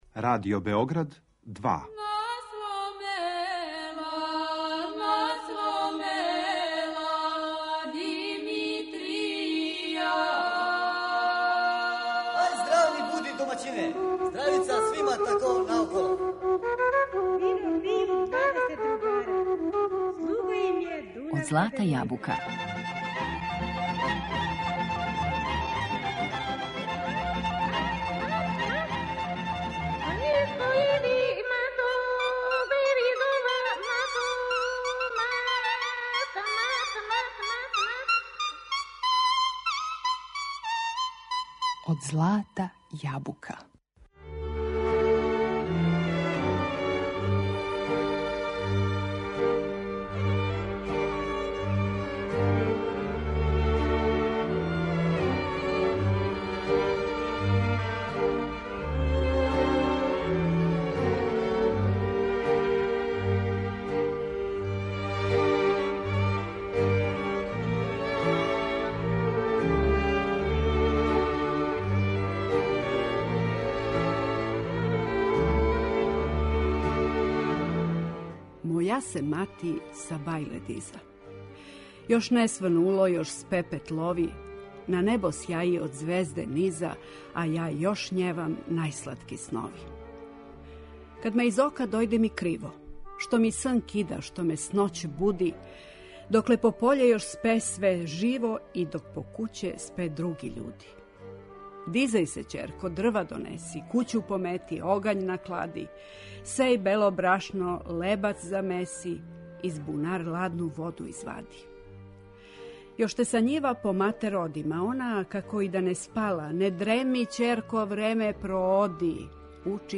Причe на сврљишко-заплањском дијалекту